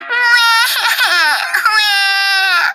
Katerina Kitty Cat Crying Sound Button - Free Download & Play